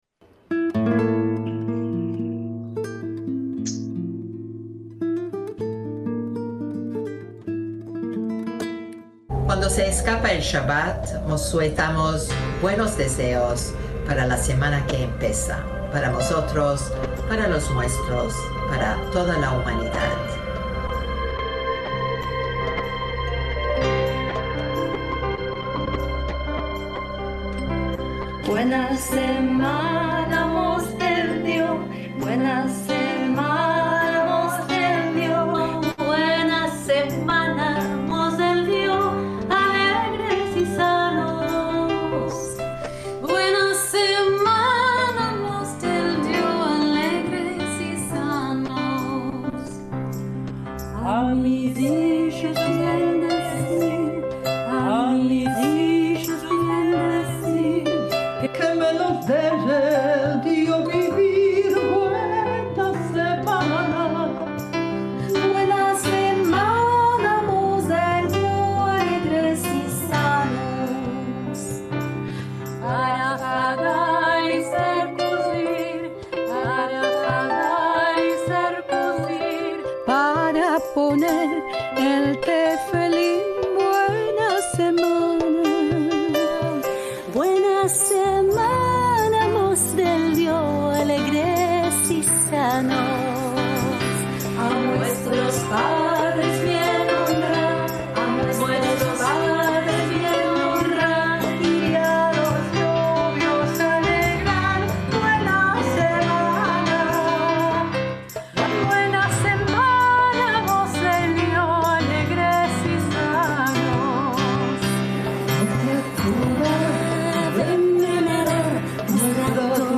Concierto Internacional de Música en Ladino (online,Centro Cultural Sefarad, 14/8/2021)
ACTOS "EN DIRECTO"
música sefardí